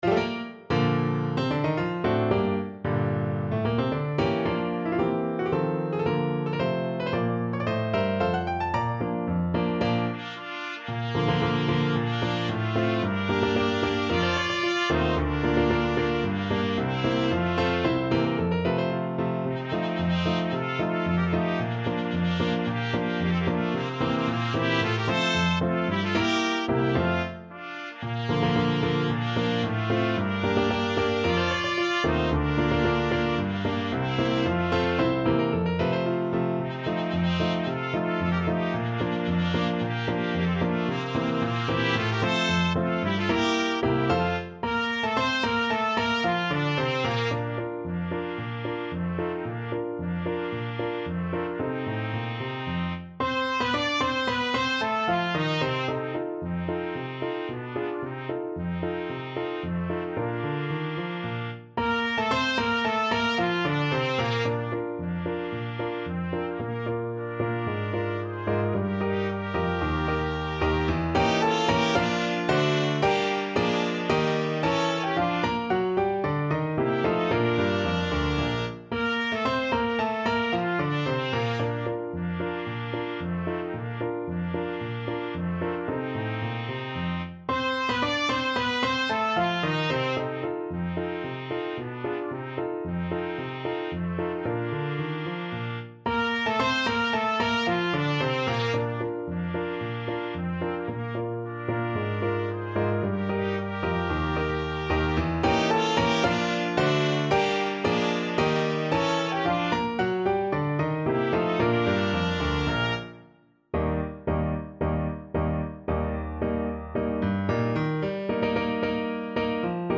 Free Sheet music for Trumpet Duet
Bb major (Sounding Pitch) C major (Trumpet in Bb) (View more Bb major Music for Trumpet Duet )
2/2 (View more 2/2 Music)
March =c.112
Classical (View more Classical Trumpet Duet Music)